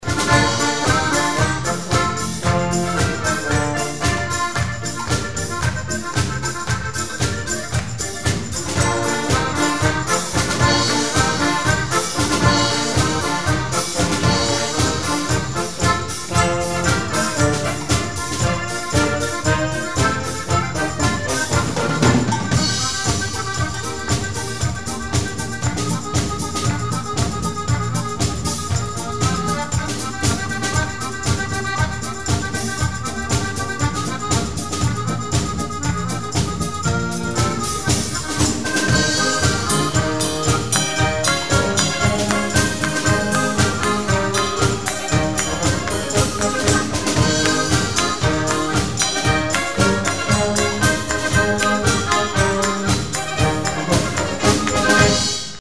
Konzert 2006 -Download-Bereich
-------Das Orchester-------